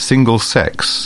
/ˈsiː.ni.ər/